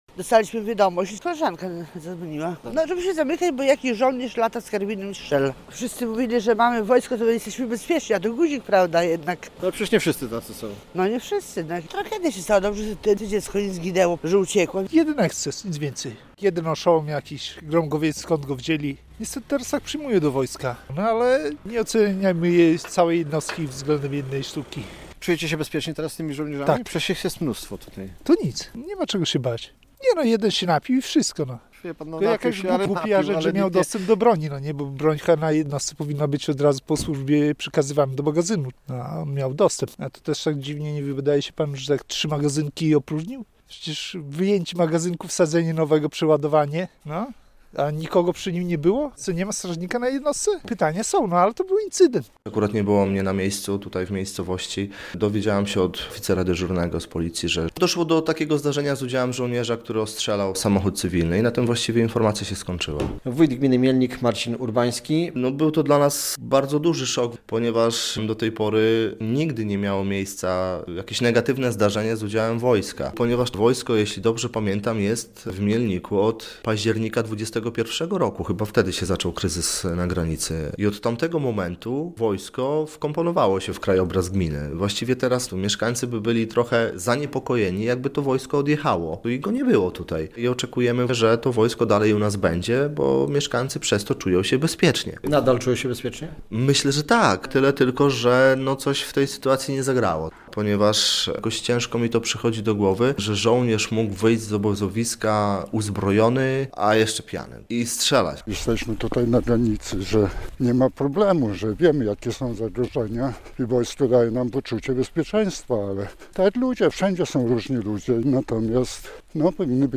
Mieszkańcy Mielnika nie dowierzają, że żołnierz wojska polskiego mógł strzelać do cywilnego samochodu - relacja